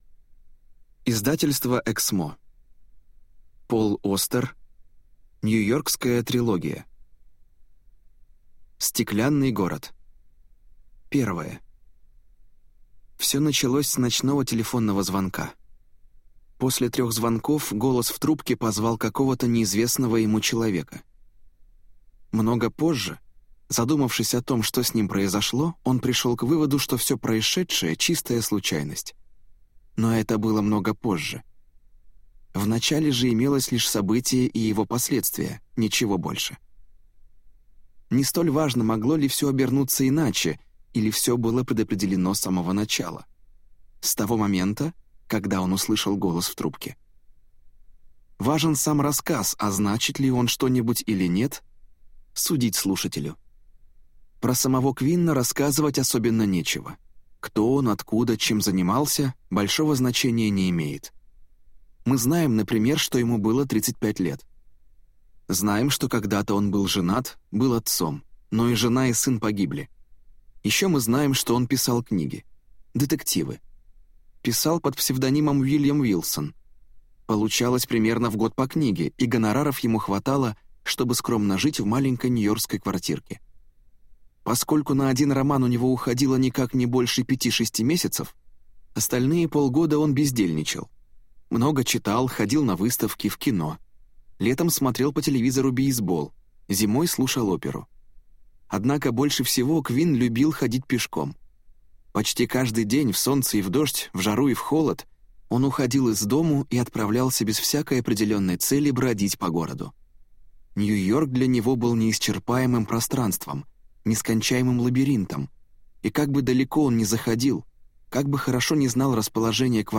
Аудиокнига Нью-Йоркская трилогия | Библиотека аудиокниг